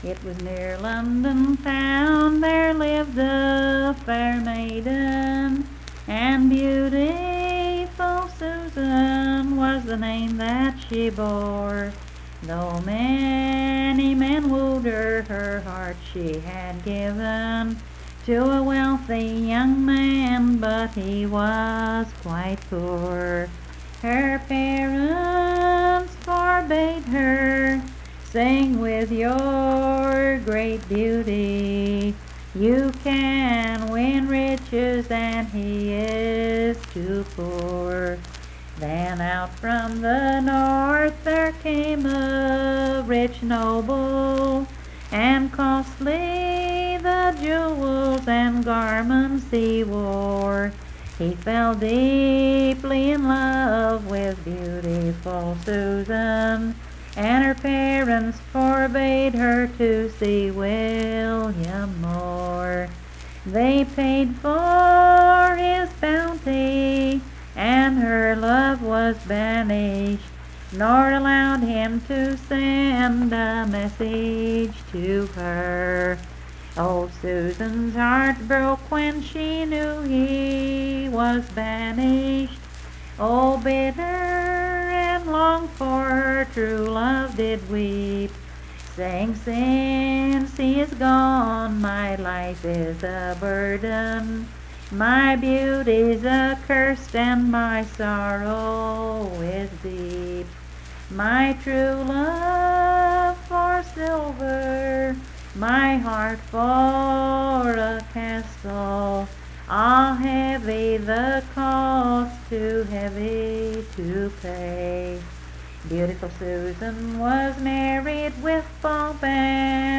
Unaccompanied vocal music
Verse-refrain 8(6).
Performed in Coalfax, Marion County, WV.
Voice (sung)